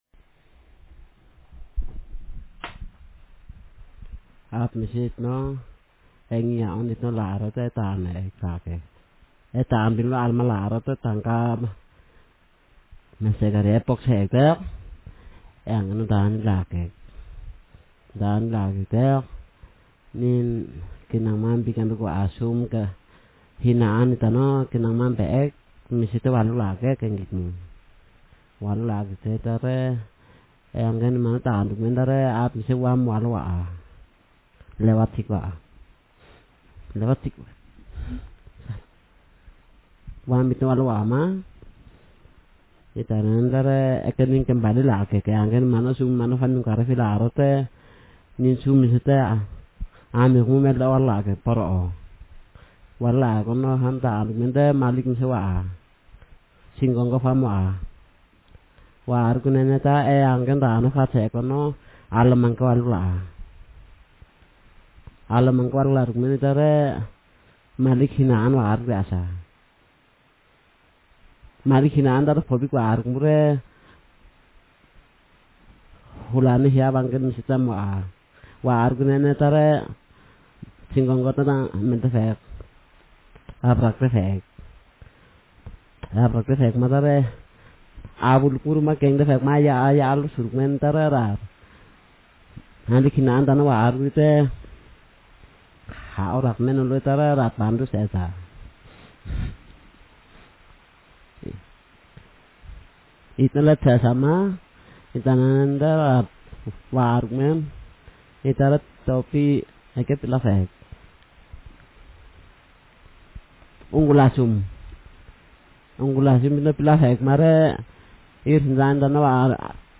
Speaker sexm
Text genrestimulus retelling